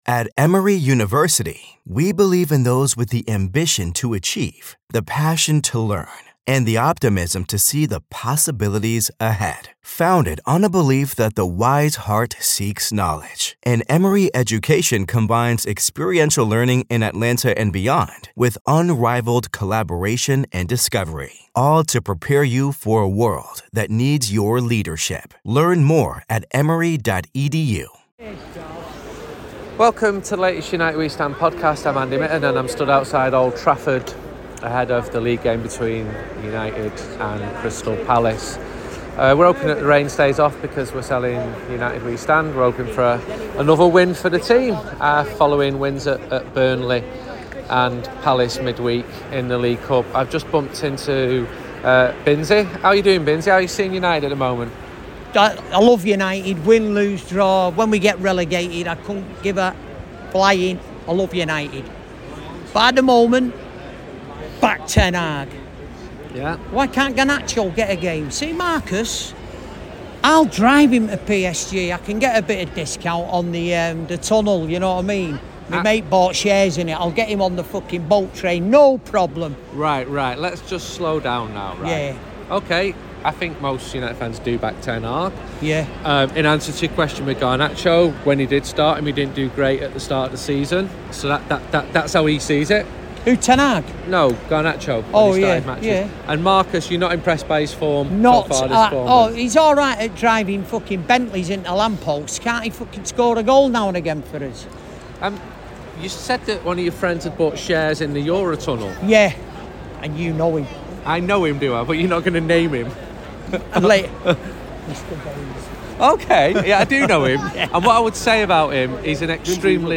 We speak to all kinds of characters and football people around Old Trafford.